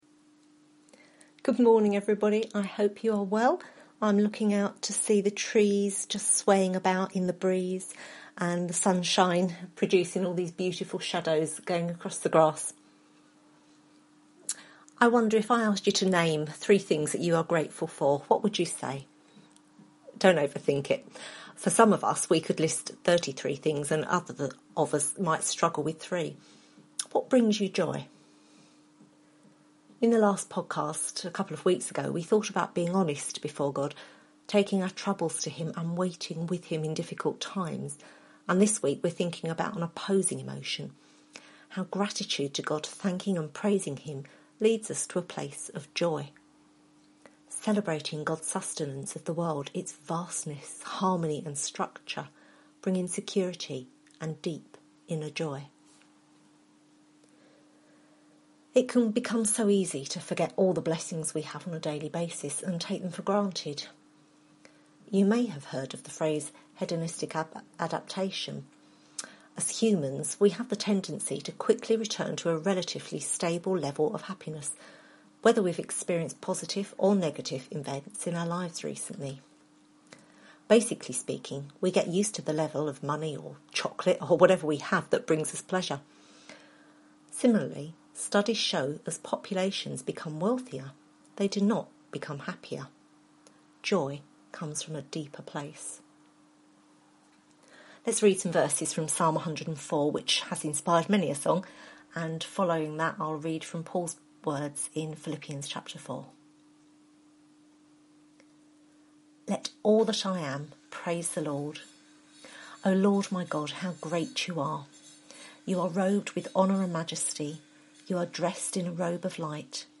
Psalms Service Type: Sunday Morning Preacher